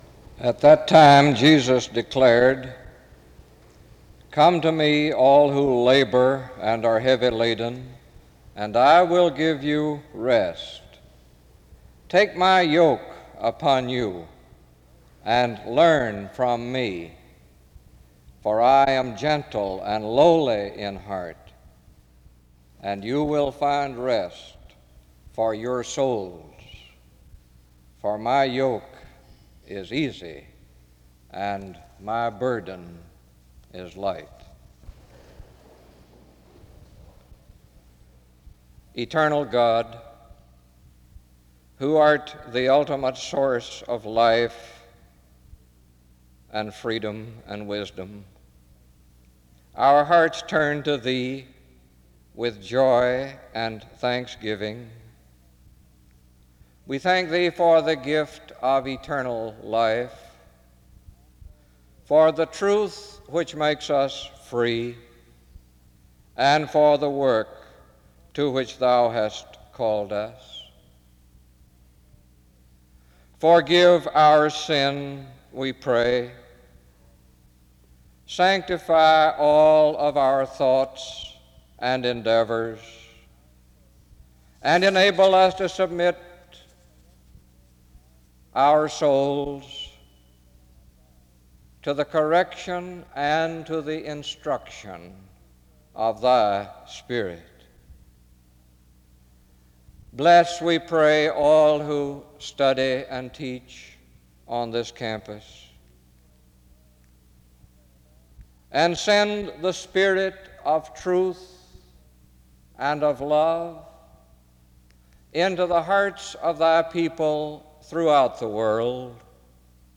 2 Corinthians 4 is read from 3:30-7:56.
SEBTS Chapel and Special Event Recordings SEBTS Chapel and Special Event Recordings